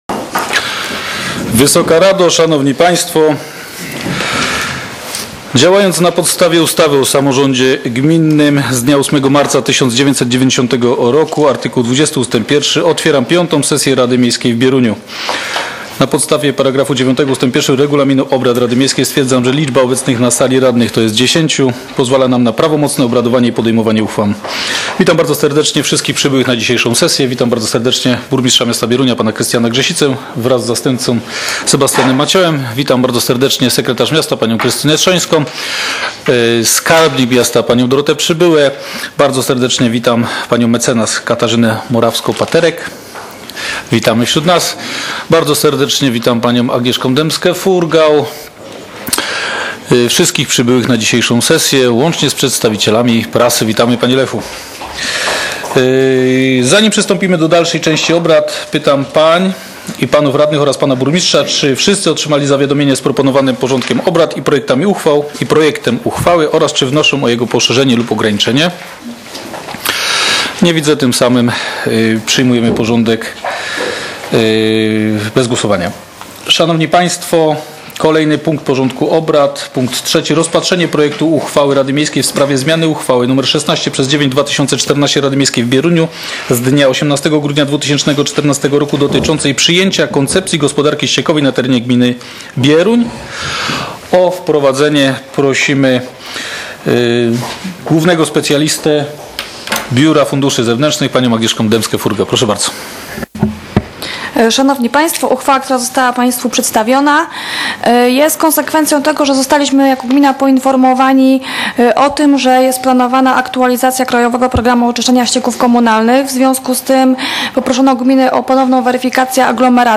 z obrad V sesji Rady Miejskiej w Bieruniu, która odbyła się w dniu 11.05.2016 r.